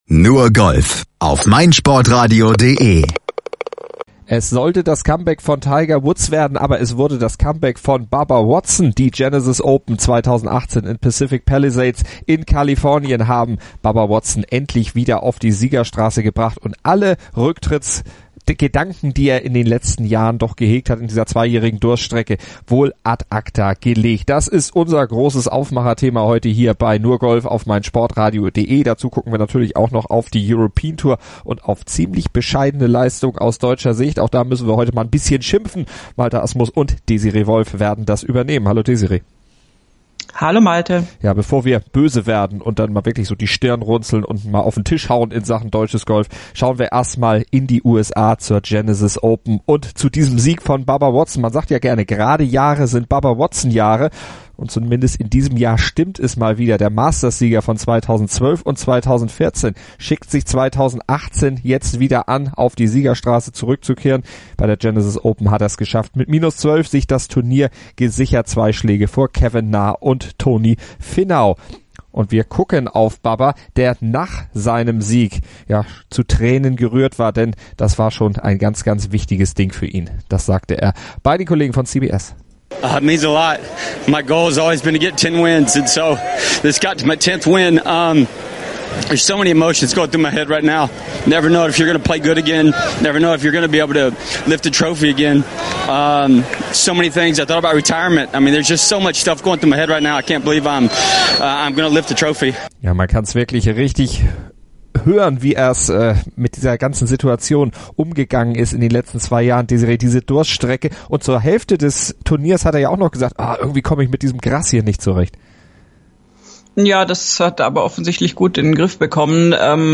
Das erzählte er kurz vor der Siegerehrung bei der Genesis Open.